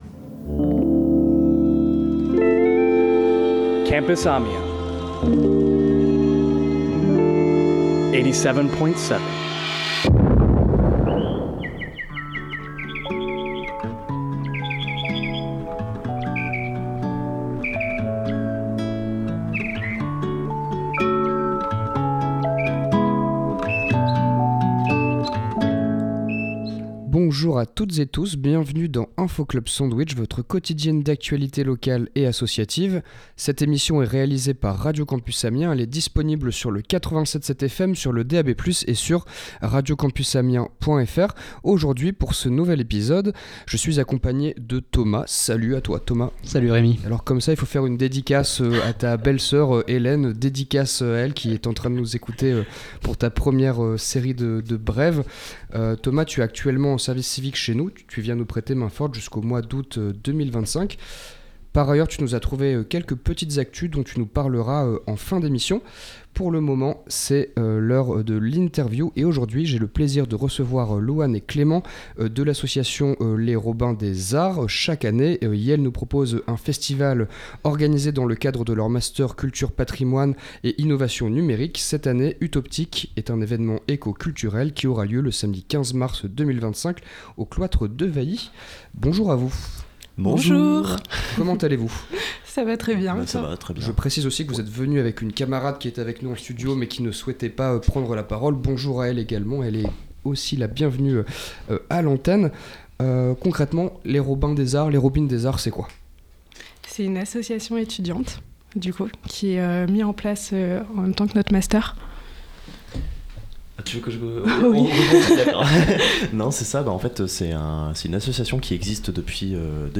Etudiantes et étudiants du Master Patrimoine, Culture et Innovation numérique de l’UPJV viennent nous présenter Utoptique, le festival éco-culturel qu’iels organisent samedi 15 mars 2025 au cloître Dewailly à Amiens. Au programme de cet événement : des concerts, des ateliers participatifs, des stands de sensibilisation et une enquête immersive intitulée L’Odyssée !